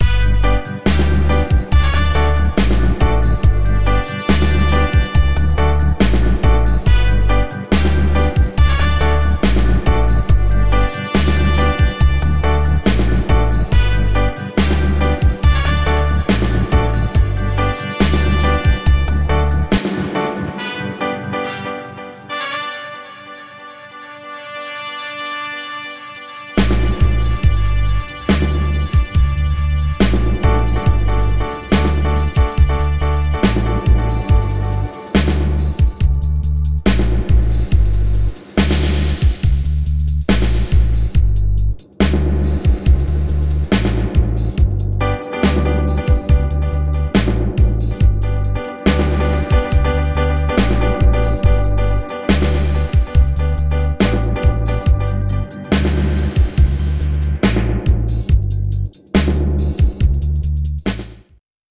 Lets give our current Riddim some Piano, Organ, Guitar and Brass. This is a standard instrumentation for a Reggae Studio Band. We're letting it play as instrumental, and then apply a little DUB mixing after that, just for fun. The Spring Reverb, by the way, is our own pre-set that we created from scratch.